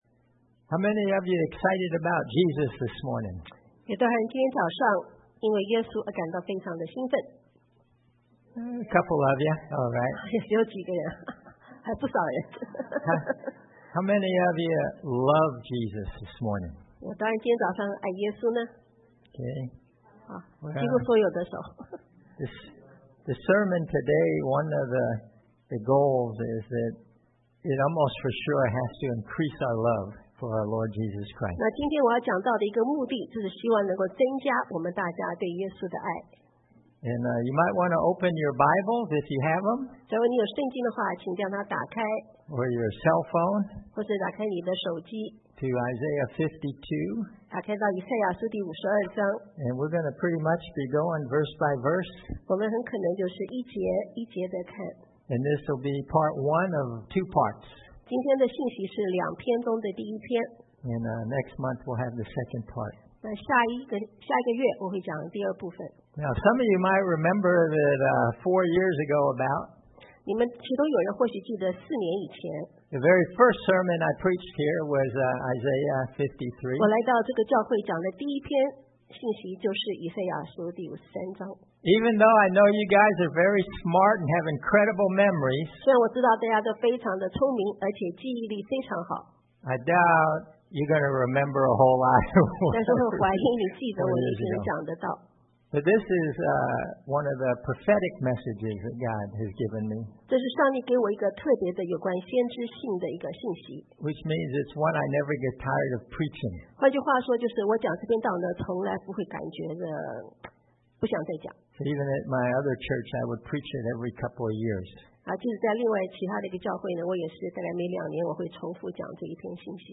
Passage: Isaiah 52:10 – 53:12 Service Type: Sunday AM Bible Text